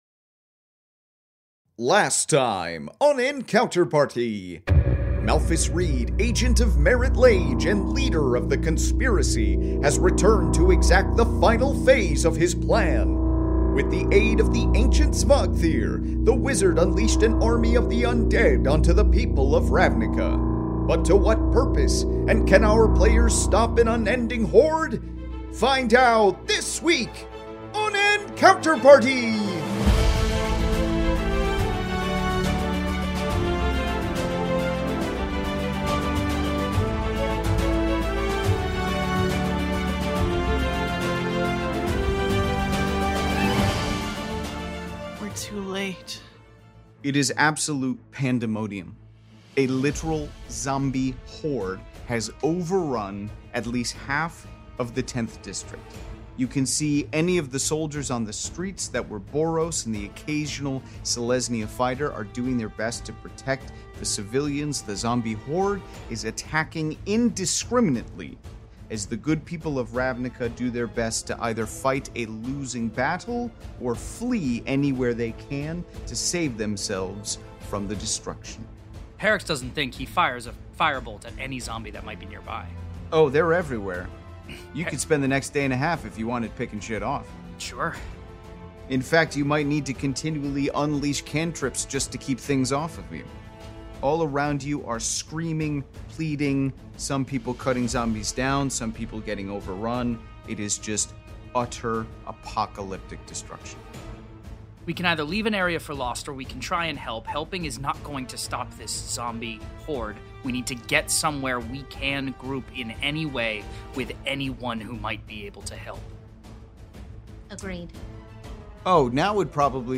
Fantasy Mystery Audio Adventure